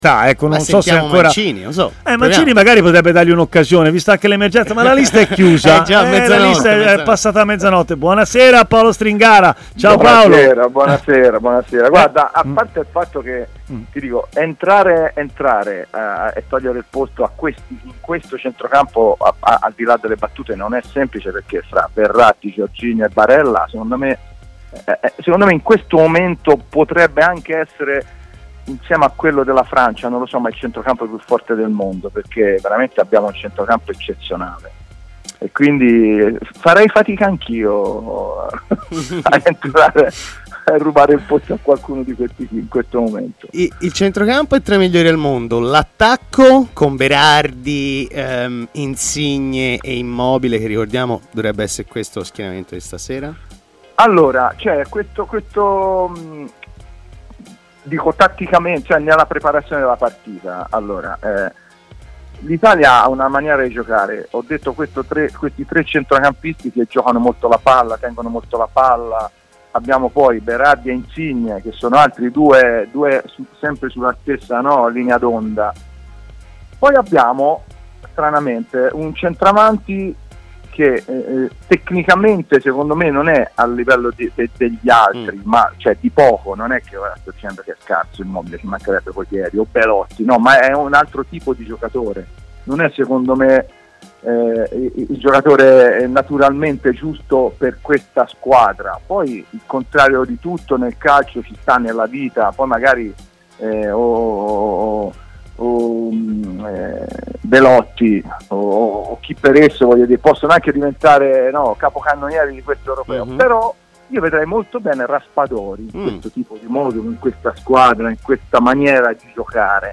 ha parlato in diretta su TMW Radio, nel corso della trasmissione Stadio Aperto